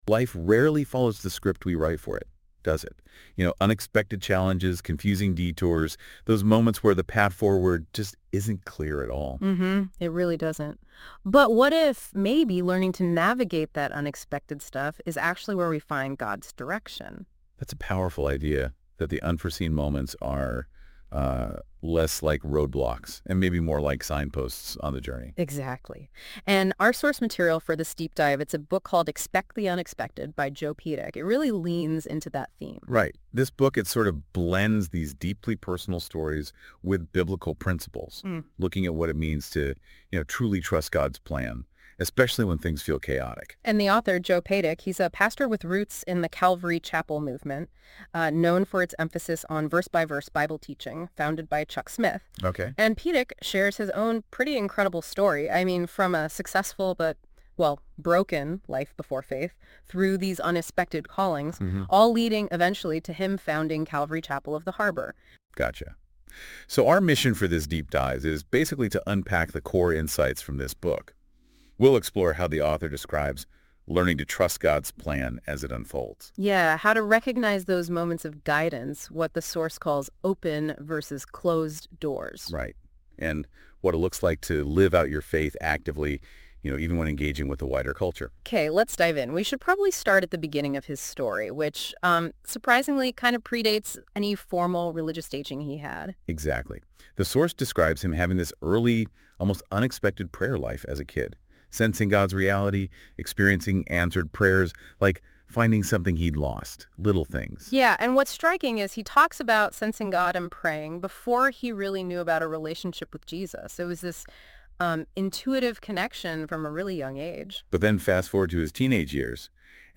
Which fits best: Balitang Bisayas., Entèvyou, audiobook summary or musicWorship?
audiobook summary